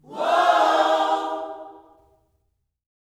WHOA-OHS 3.wav